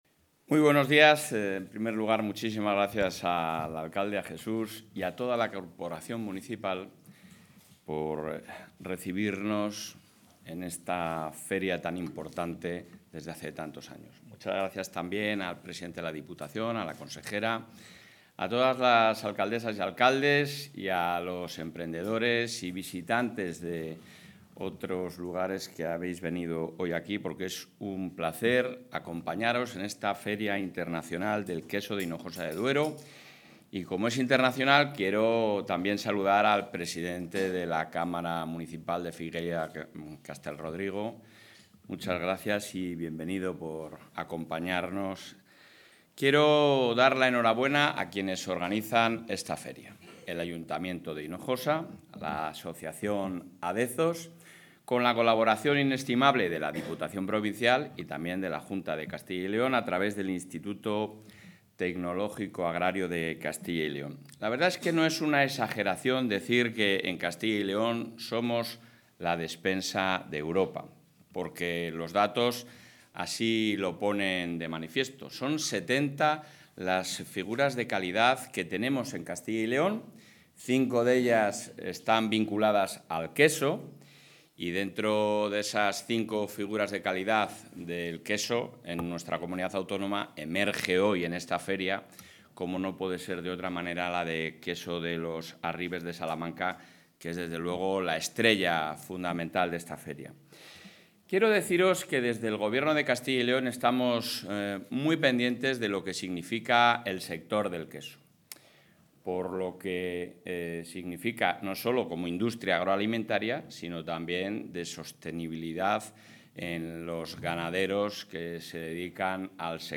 El presidente de la Junta de Castilla y León, Alfonso Fernández Mañueco, ha asistido hoy al acto de inauguración de la...
Intervención del presidente de la Junta.